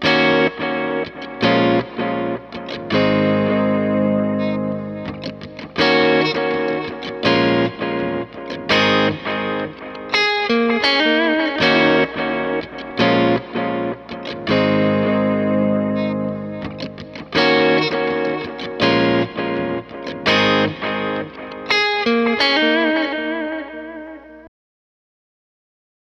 Sunny_83bpm_Ebm
Guitar_Sunny_83bpm-Ebm.wav